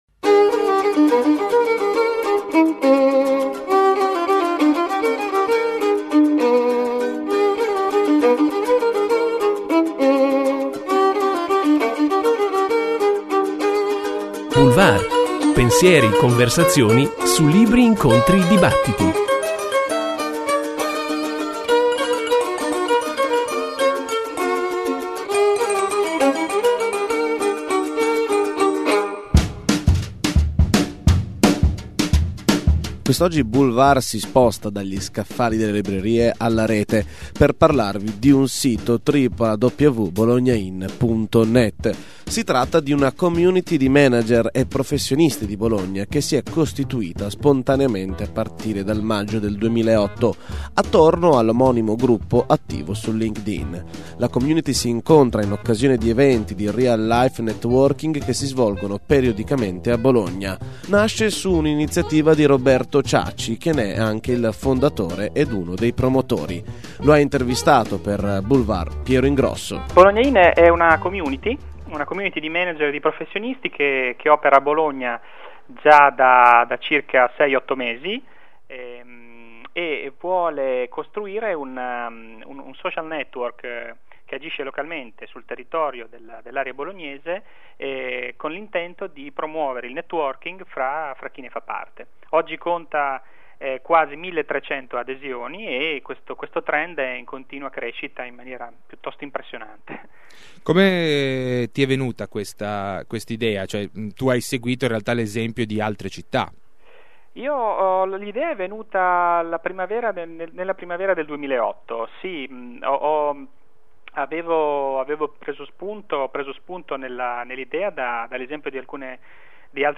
BolognaIN: intervista